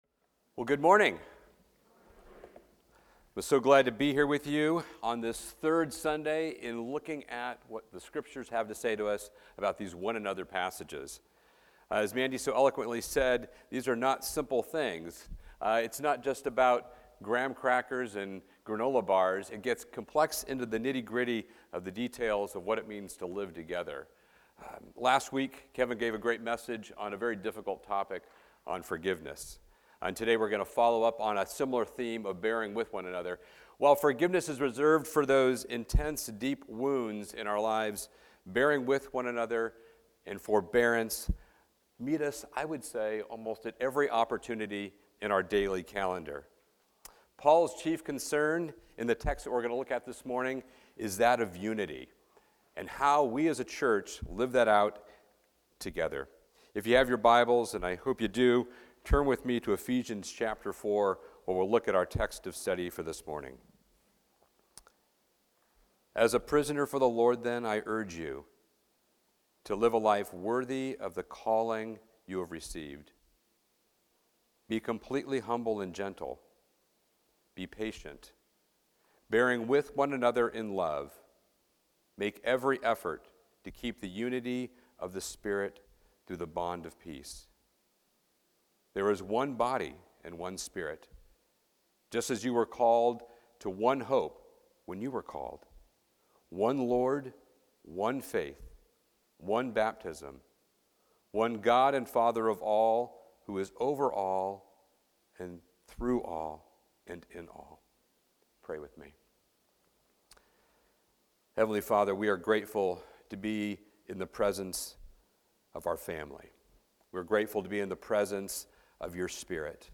Requests